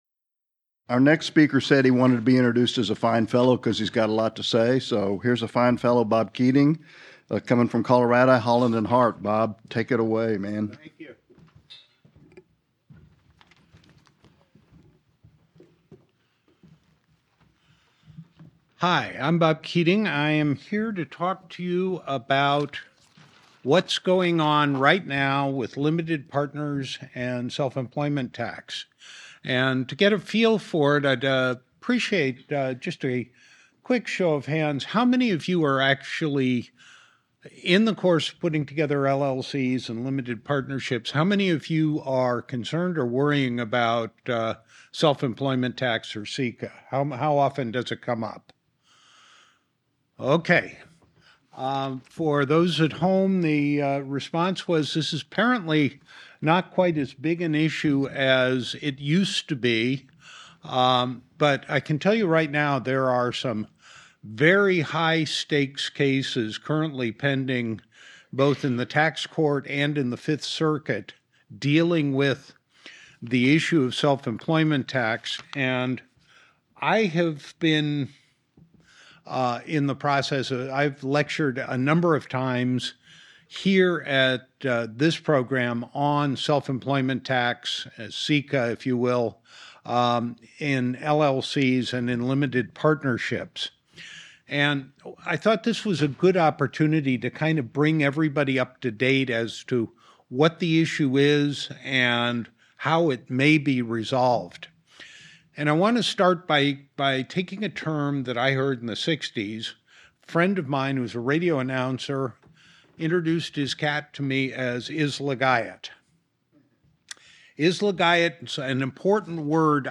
This panel discusses the current treatment of individuals’ distributive share of partnership income for self-employment tax purposes, including the recent Tax Court pronouncement on treatment of individuals who are limited partners for purposes of state law.